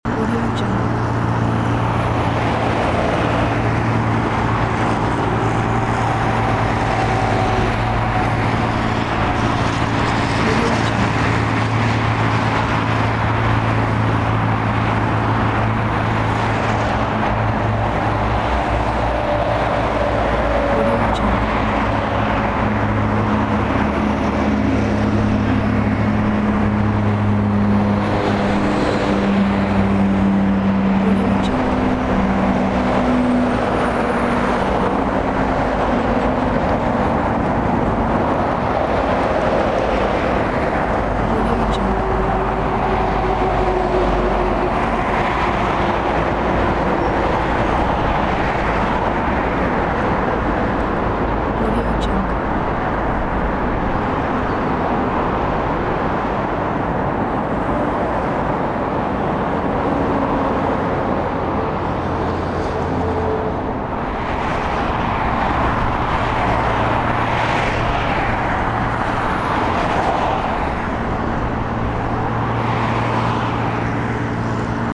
دانلود افکت صوتی ترافیک و شلوغی جاده یا اتوبان 3
دانلود افکت صوتی شهری